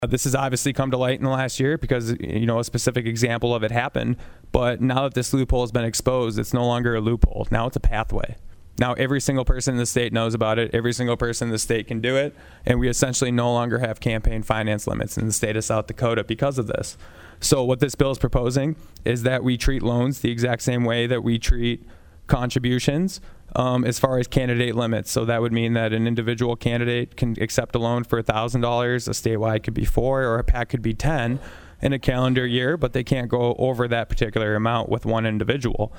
Rohl discuss what happened last year is what prompted this bill.